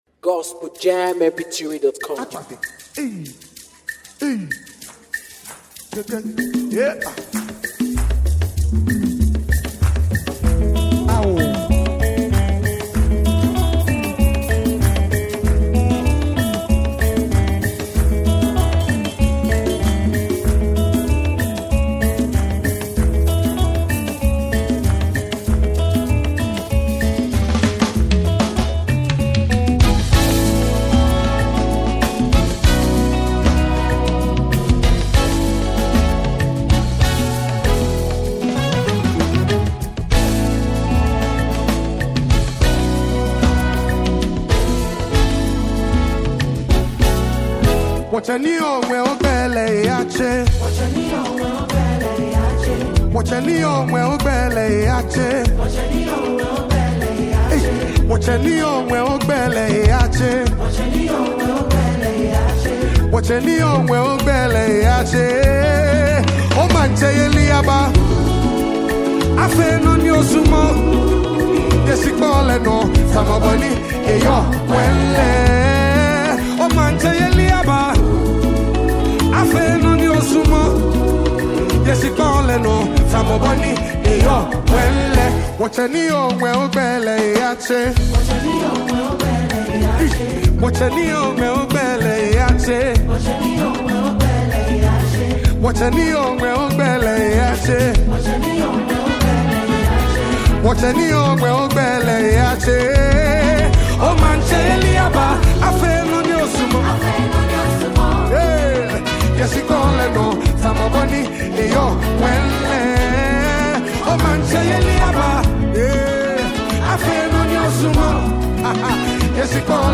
powerful worship song
With deep, reverent lyrics and a strong worship atmosphere